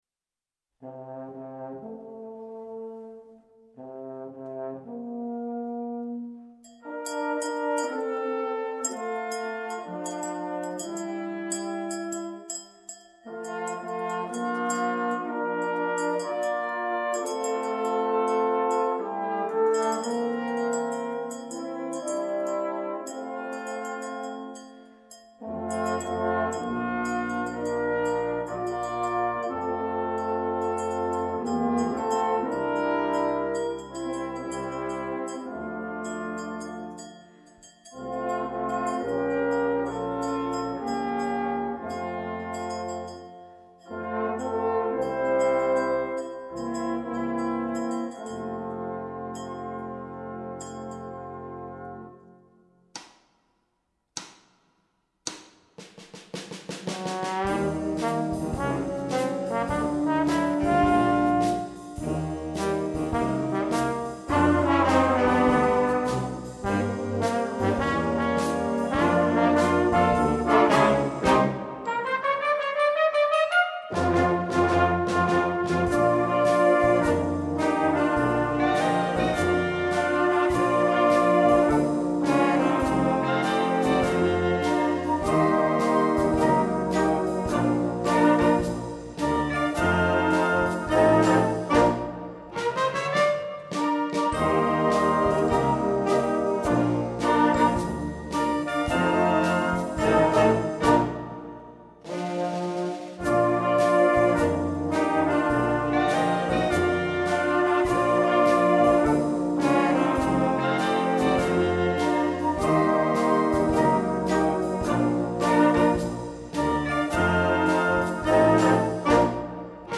Gattung: Piccola Sinfonia - Kleine Ouvertüre
Besetzung: Blasorchester